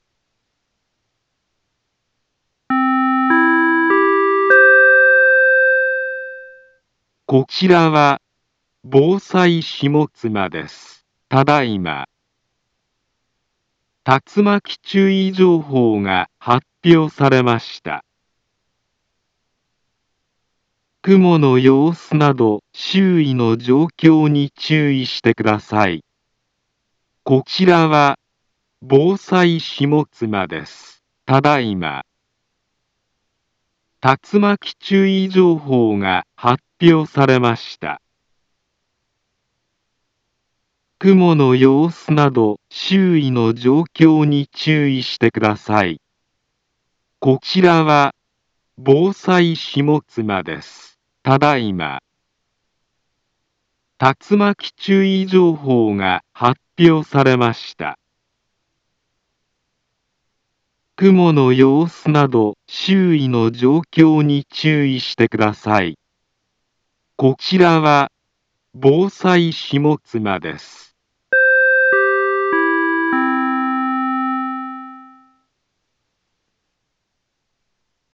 Back Home Ｊアラート情報 音声放送 再生 災害情報 カテゴリ：J-ALERT 登録日時：2024-08-09 16:09:31 インフォメーション：茨城県南部は、竜巻などの激しい突風が発生しやすい気象状況になっています。